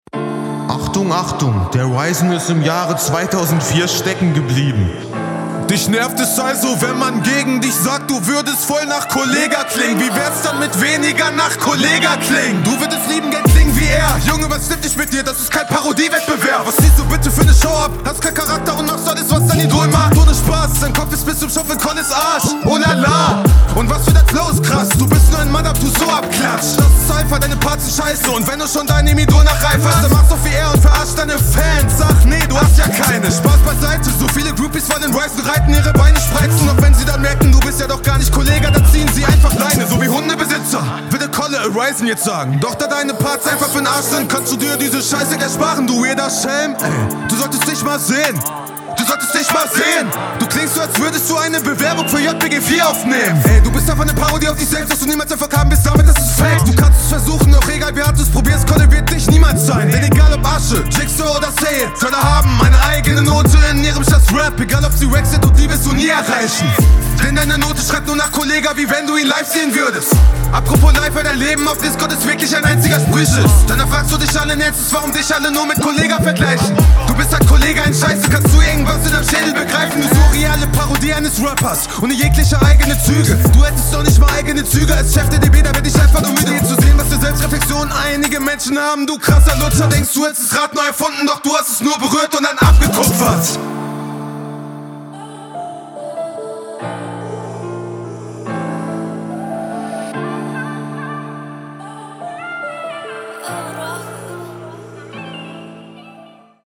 ooo, du kommst gleich mal sehr mächtig auf den beat. bisi vernuschelt an manchen stellen …
Du bis stimmlich schon eher da, wo ich das auf diesen Beat selbst ansiedeln würde!